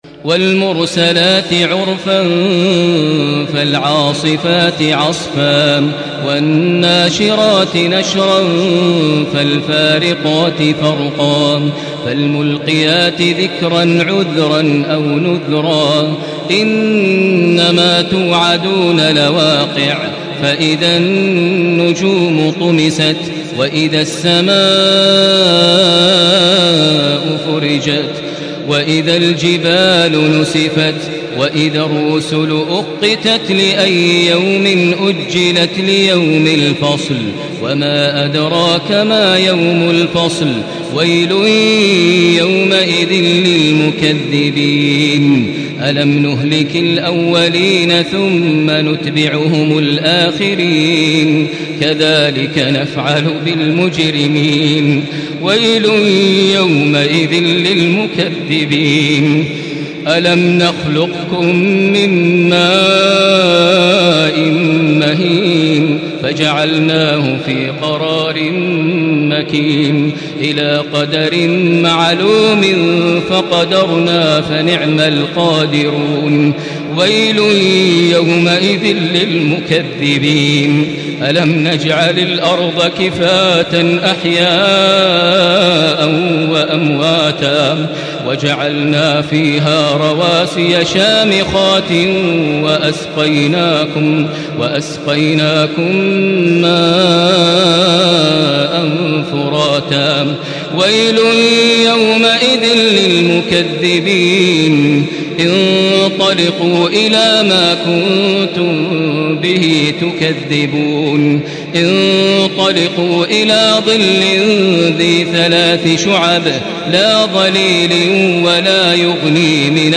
Surah Mürselat MP3 by Makkah Taraweeh 1435 in Hafs An Asim narration.
Murattal Hafs An Asim